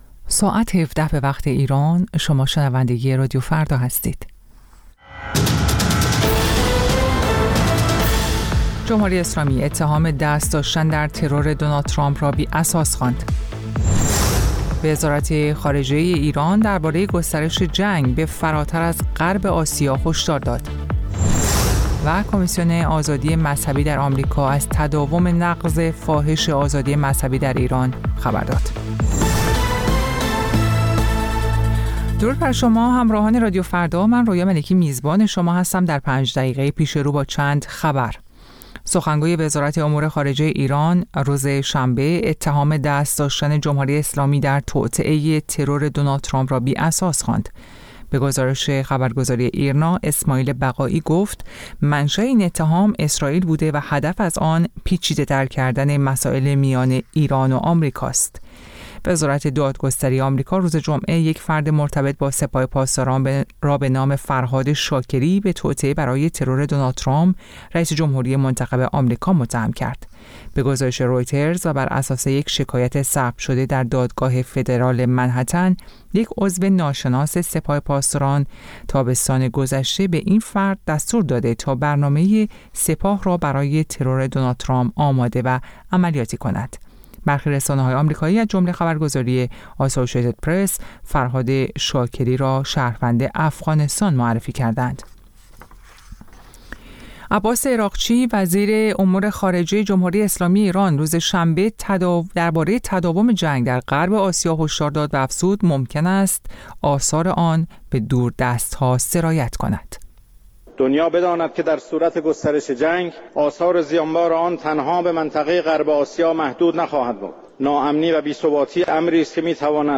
سرخط خبرها ۱۷:۰۰